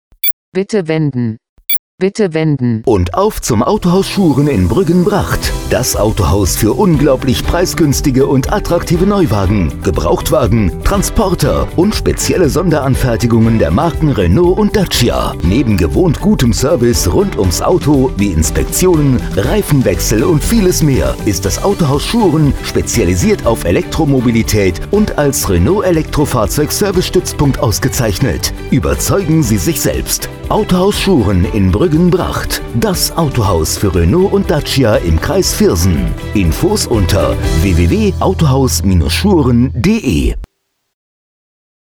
Radiospot Autohaus Schouren im IGL-Radio